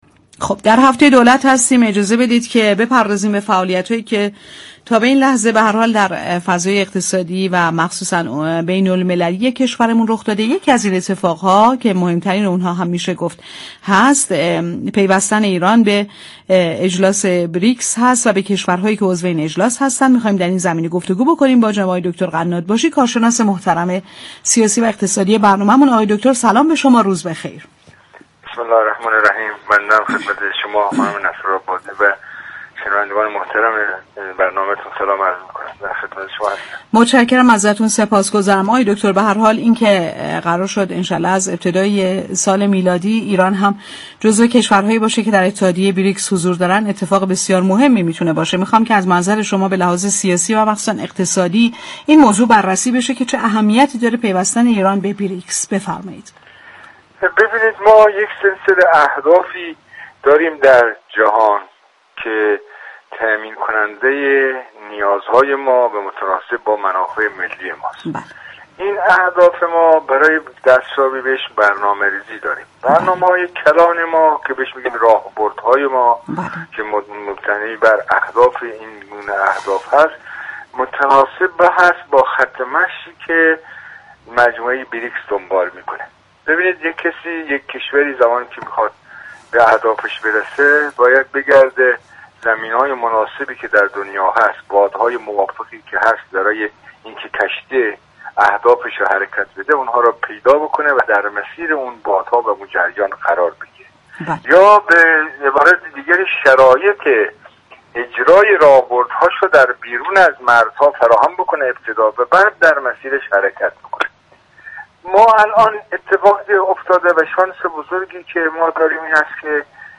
كارشناس سیاسی در گفت و گو با «بازار تهران»